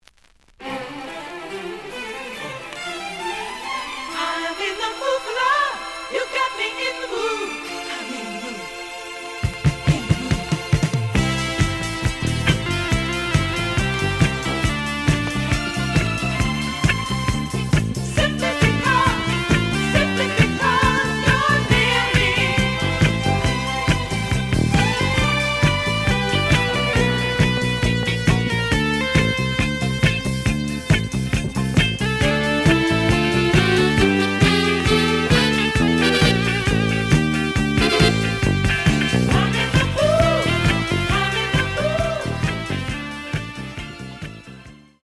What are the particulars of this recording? The audio sample is recorded from the actual item. ●Format: 7 inch Slight edge warp.